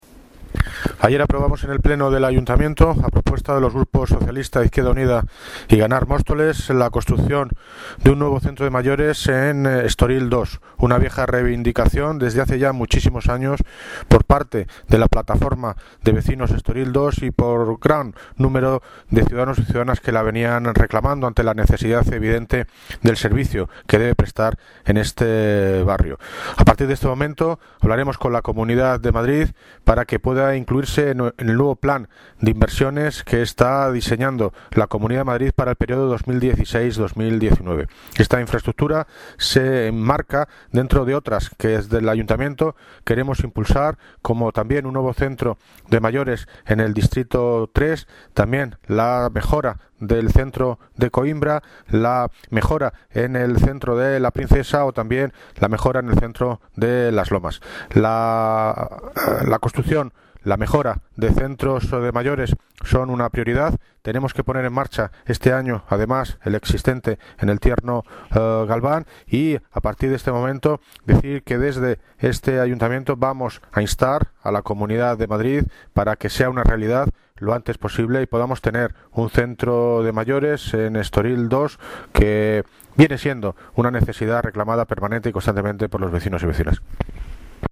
Audio - David Lucas (Alcalde de Móstoles) Sobre Residencia de Mayores